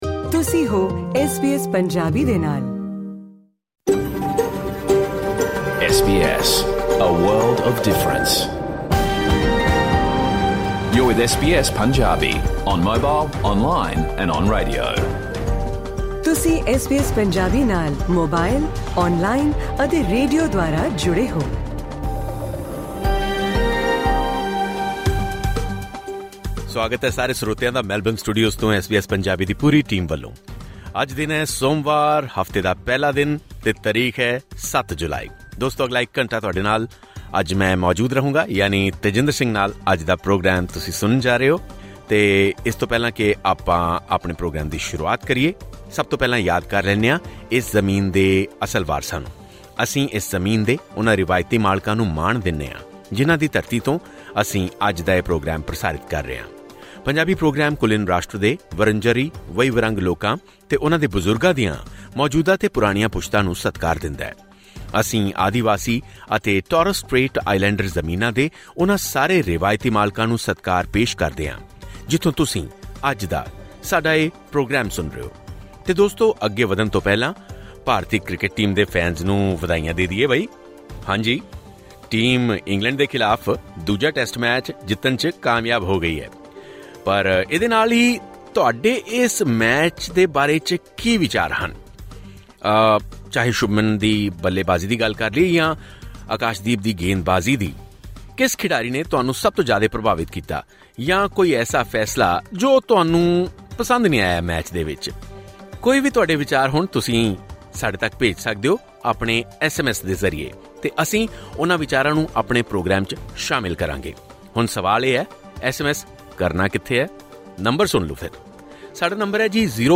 In this radio program, get information about the main news of the country and abroad as well as important news of Punjab along with some other reports.